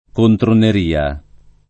kontroner&a]